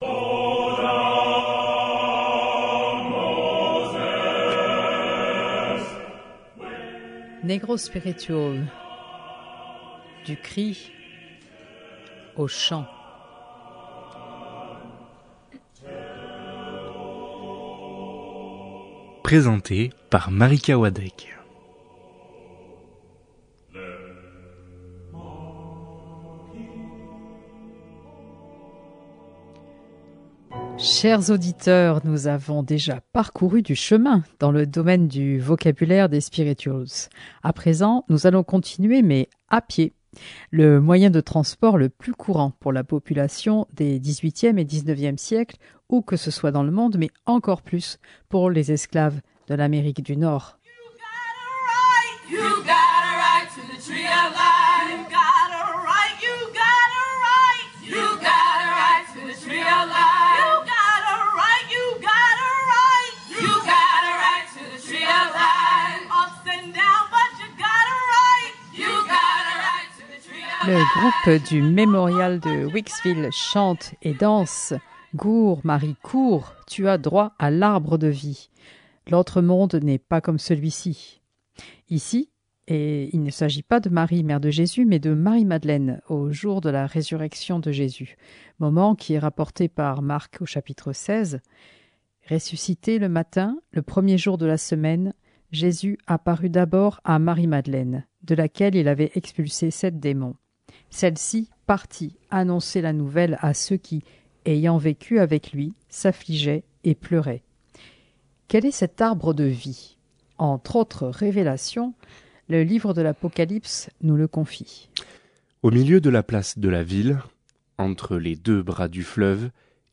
Productrice de Negro spiritual : du cri au chant